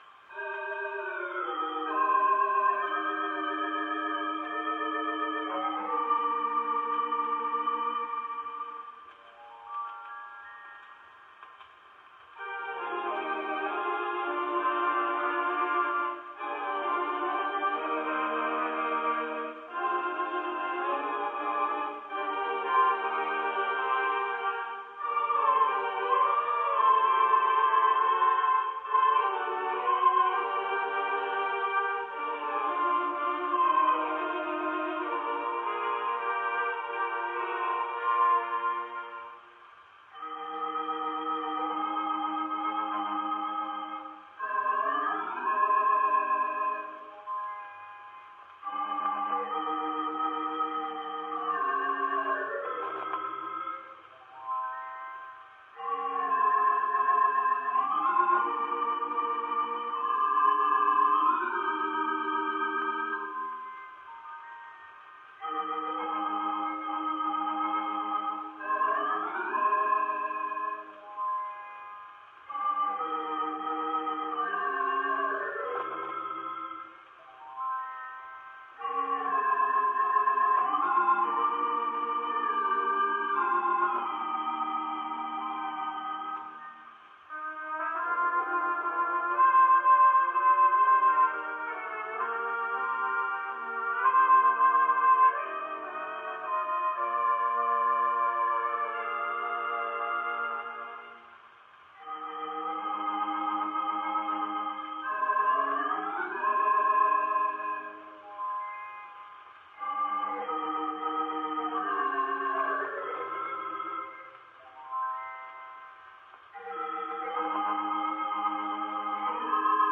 Played on the Morton Organ, Loew's Valencia Theatre